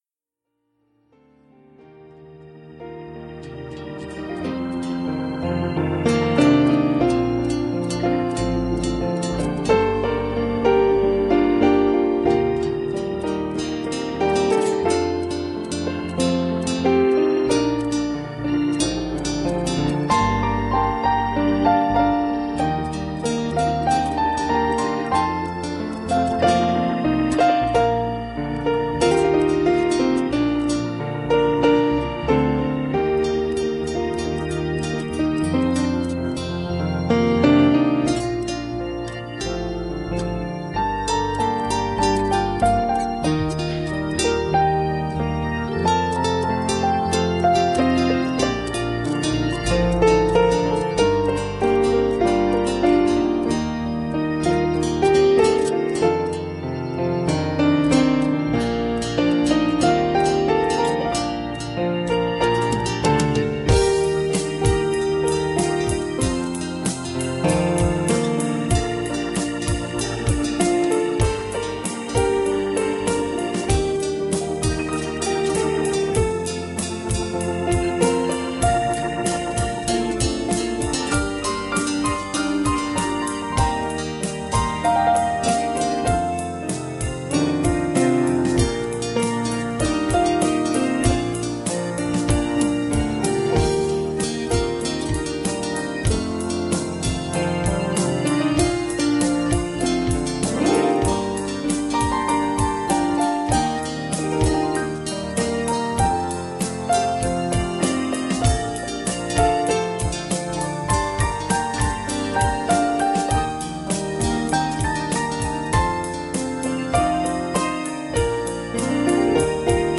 Ruth 2:8 Service Type: Special Service Bible Text